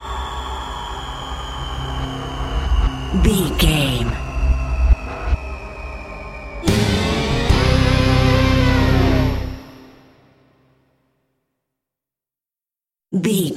Thriller
Aeolian/Minor
synthesiser